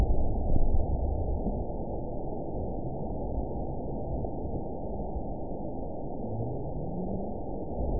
event 917273 date 03/26/23 time 13:46:43 GMT (2 years, 1 month ago) score 9.17 location TSS-AB04 detected by nrw target species NRW annotations +NRW Spectrogram: Frequency (kHz) vs. Time (s) audio not available .wav